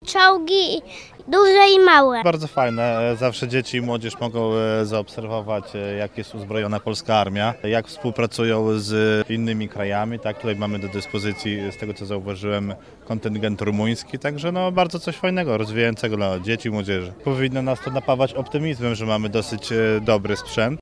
Imprezę zorganizowano na placu Pałacu Sanguszków w Lubartowie.